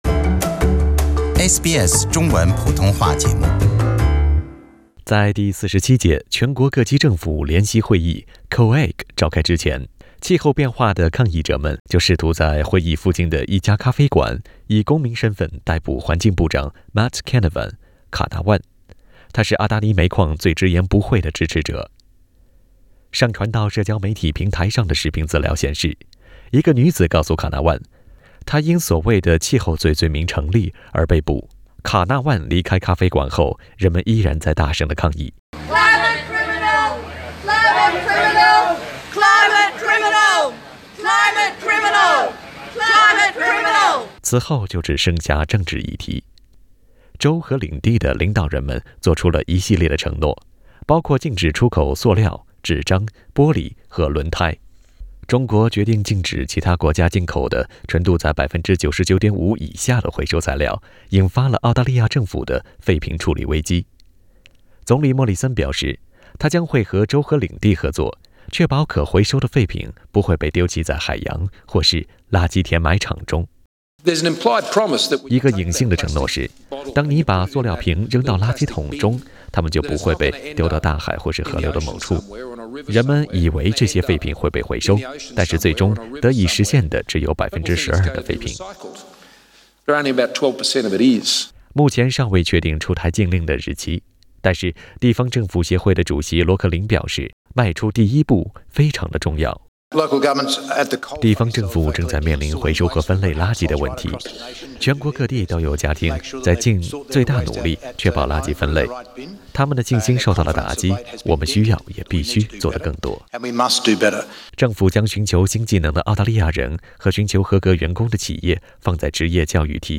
Source: AAP SBS 普通话电台 View Podcast Series Follow and Subscribe Apple Podcasts YouTube Spotify Download (10.06MB) Download the SBS Audio app Available on iOS and Android 上周五，第47届全国各级政府联席会议,即 COAG在凯恩斯召开。